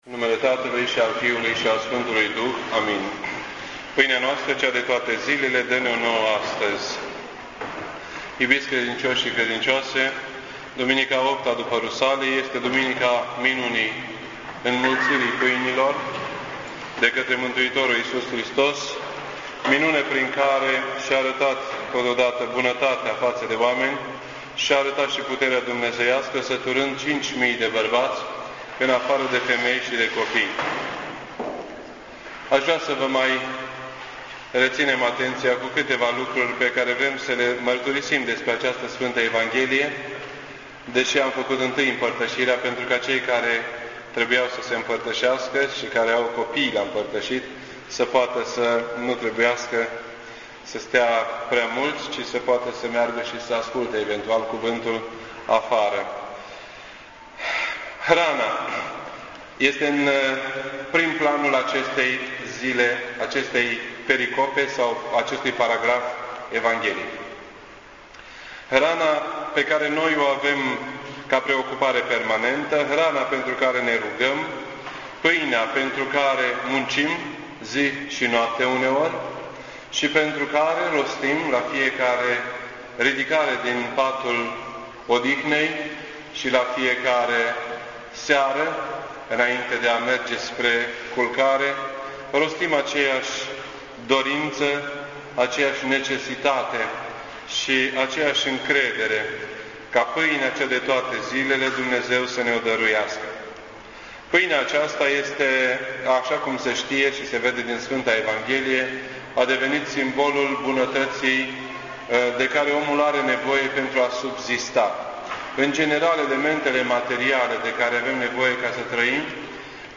This entry was posted on Sunday, August 10th, 2008 at 9:31 AM and is filed under Predici ortodoxe in format audio.